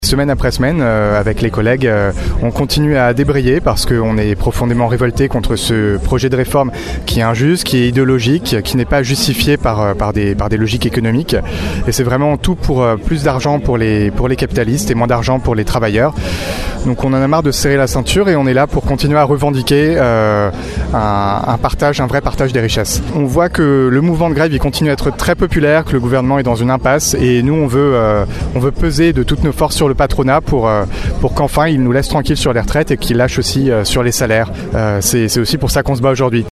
Hier matin, lors du rassemblement place Colbert à Rochefort.